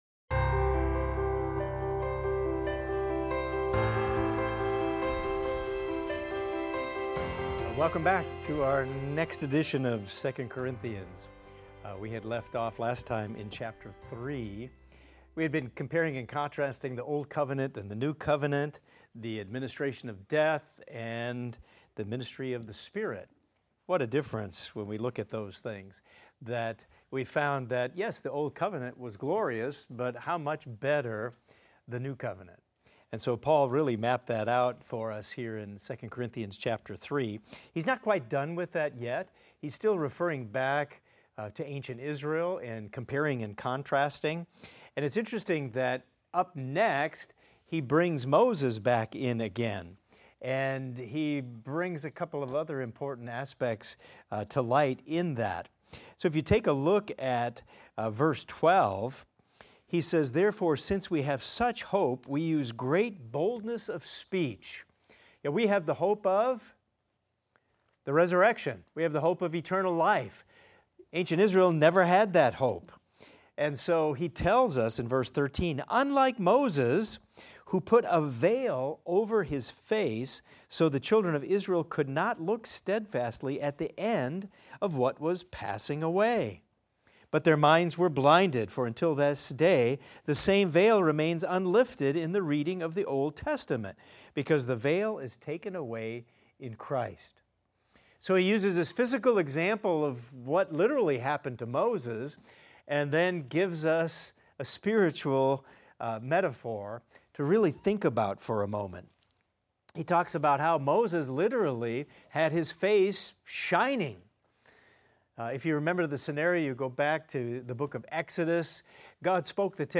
In this class we will discuss 2 Corinthians 3:13 thru 2 Corinthians 4:18 and examine the following: Paul contrasts Moses' veil with the unveiled glory of Christ, symbolizing the clarity of the new covenant.